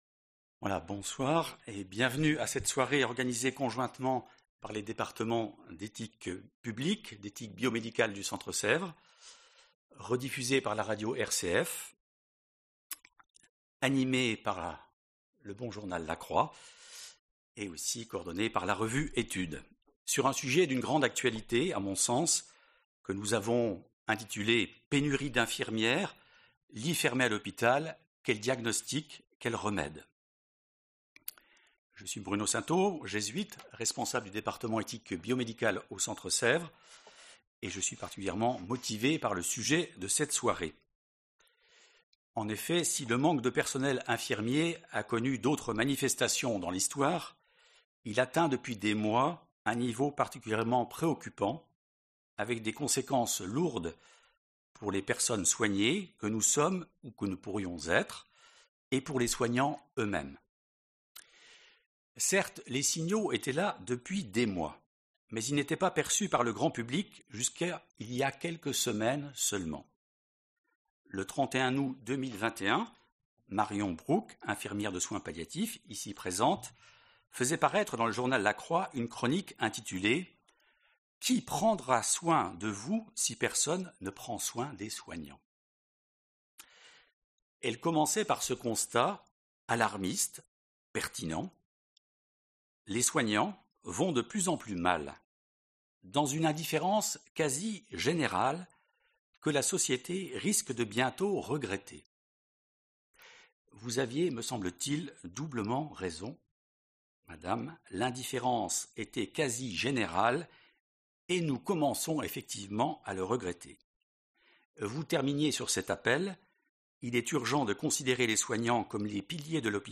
Le premier mardi de chaque mois (sauf décalage dû aux vacances) se tient au Centre Sèvres une rencontre avec deux ou trois invités, experts dans leurs domaines, destinée à nourrir la réflexion sur des sujets suggérés par l’actualité.